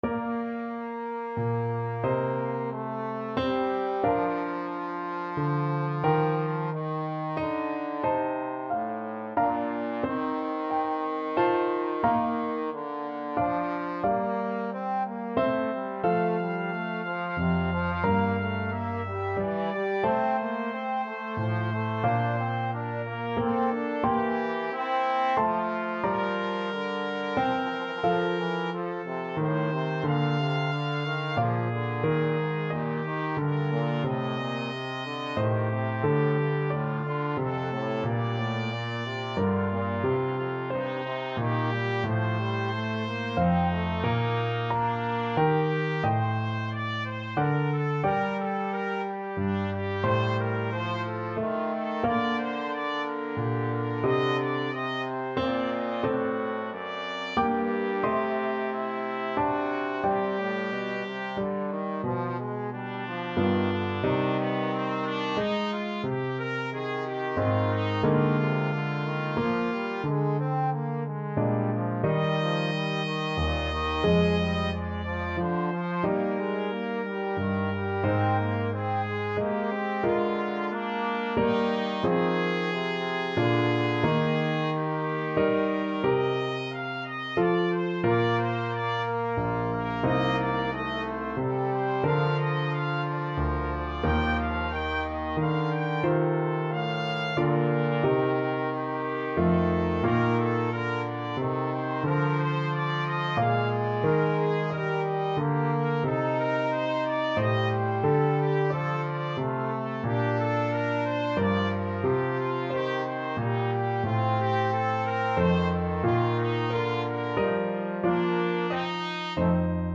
Largo ma non tanto ( = c. 90)
3/4 (View more 3/4 Music)
Classical (View more Classical Trumpet-Trombone Duet Music)